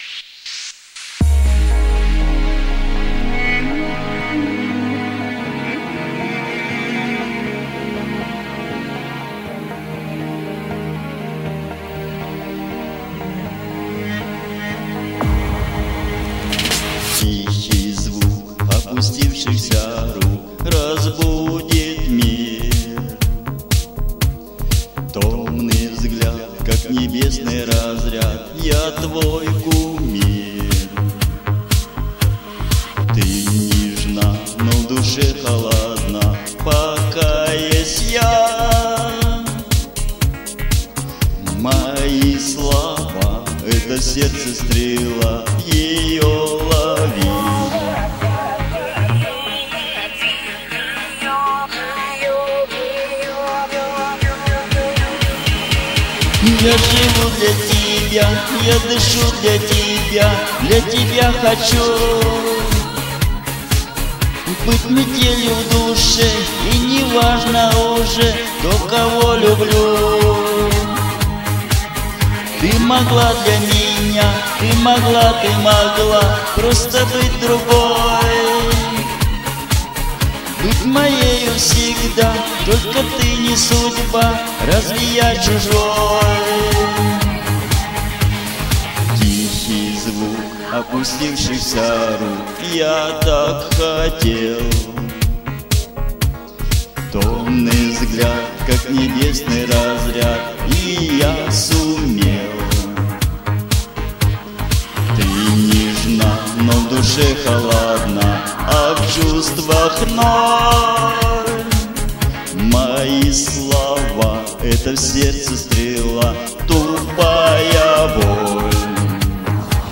и старательно поете женскую песню про душу ulybka